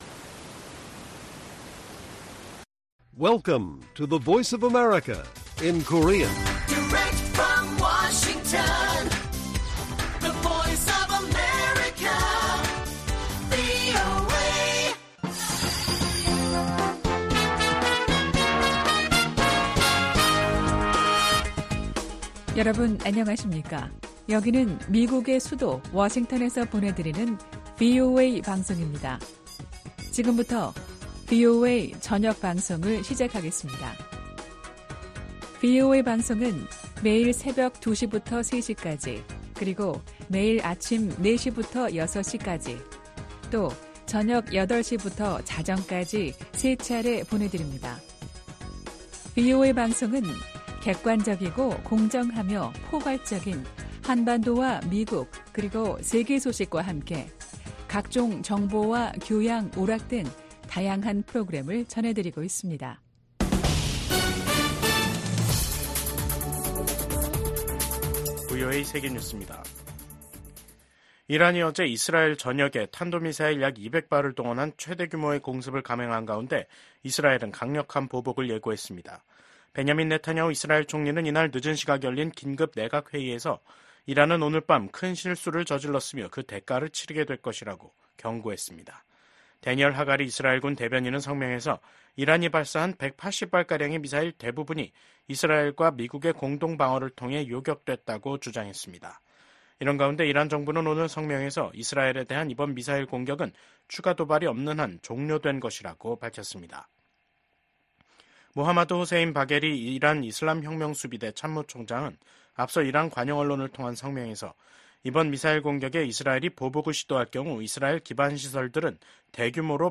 VOA 한국어 간판 뉴스 프로그램 '뉴스 투데이', 2024년 10월 2일 1부 방송입니다. 민주당의 팀 월즈 부통령 후보와 공화당의 J.D. 밴스 후보가 첫 TV 토론에서 안보와 경제, 이민 문제를 두고 첨예한 입장 차를 보였습니다. 토니 블링컨 미국 국무장관은 북한, 중국, 러시아, 이란을 국제 질서를 흔드는 세력으로 규정하며 동맹과 단호히 대응해야 한다고 밝혔습니다.